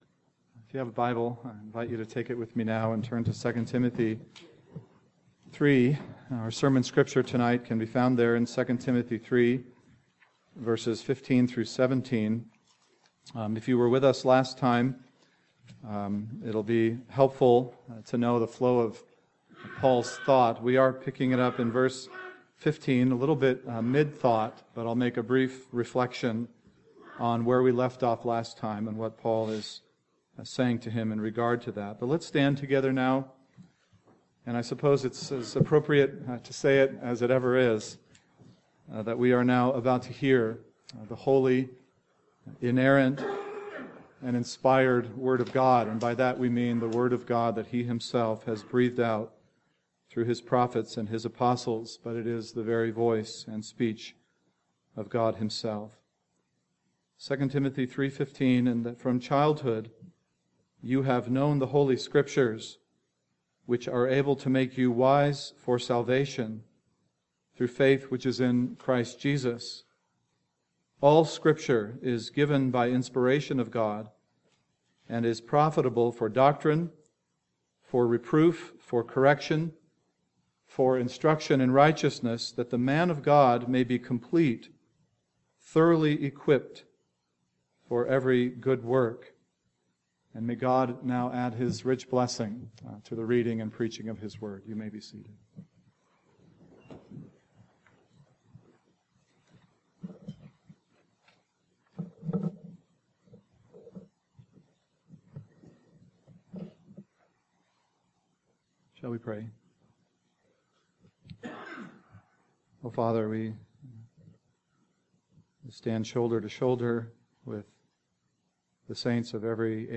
PM Sermon